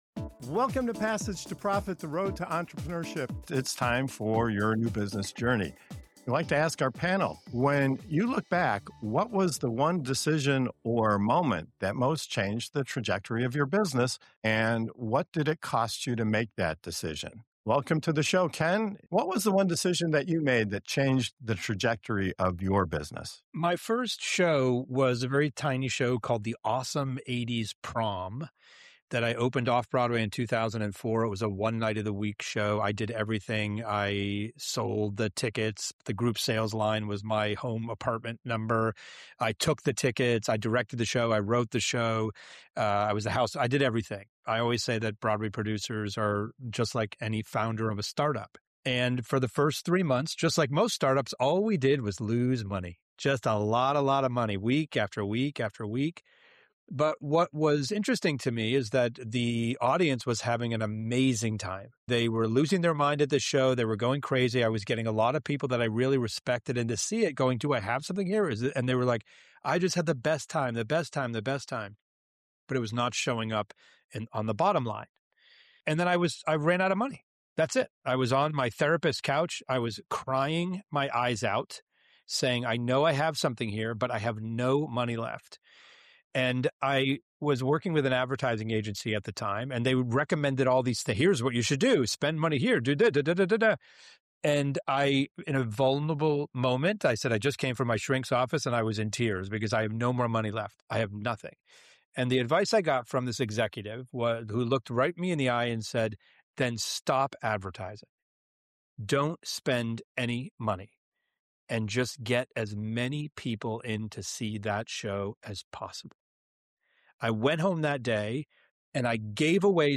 In this powerful episode, entrepreneurs share the pivotal moments that transformed their journeys, from risking everything and giving away a product to spark explosive word-of-mouth growth, to grinding through early rejection and learning the true cost of building trust and visibility.